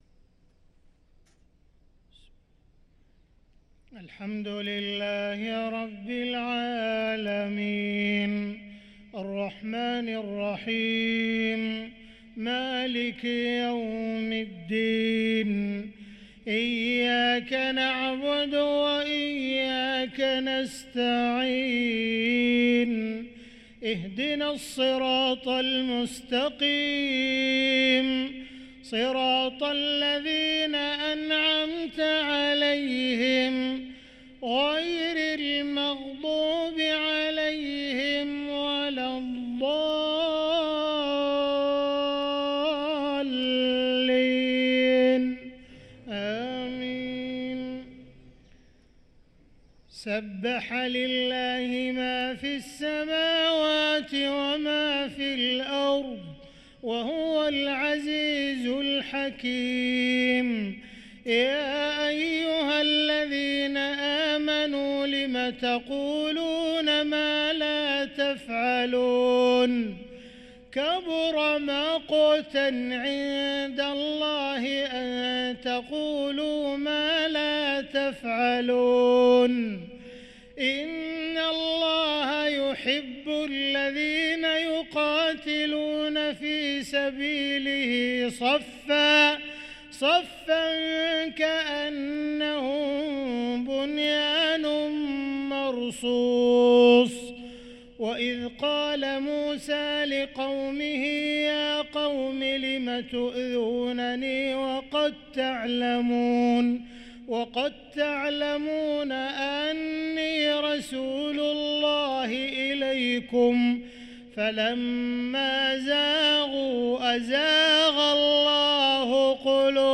صلاة الفجر للقارئ عبدالرحمن السديس 30 ربيع الآخر 1445 هـ
تِلَاوَات الْحَرَمَيْن .